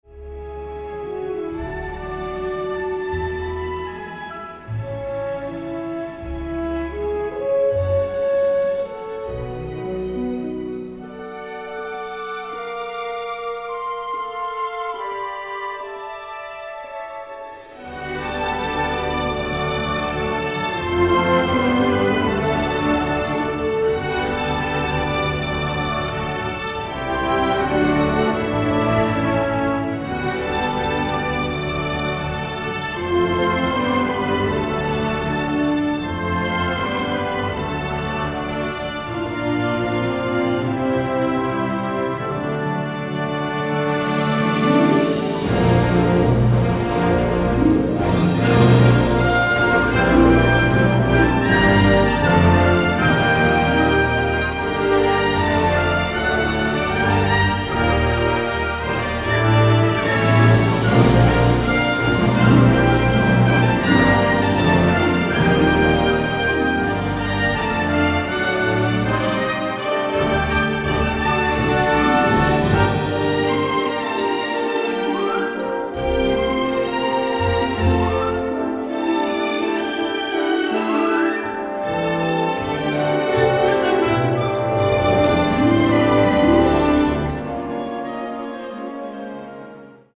powerhouse, original orchestral material